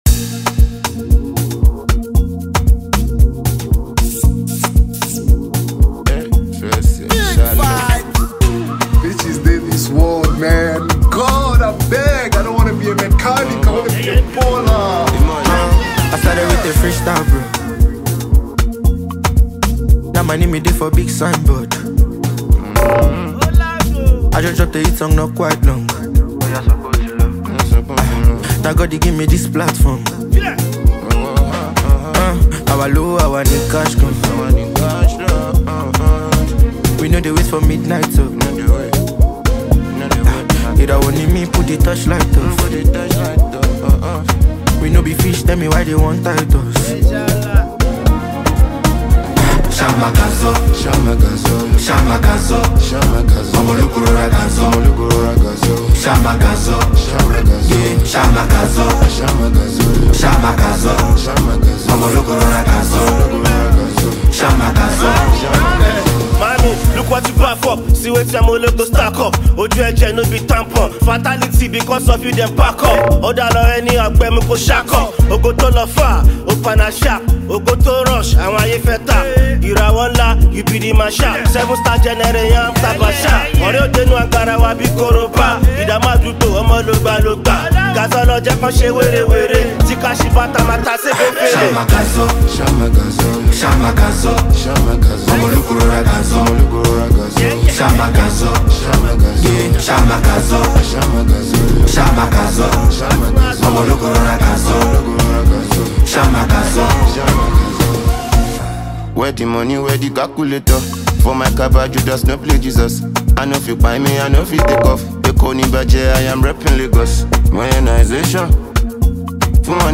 contemporary Nigerian music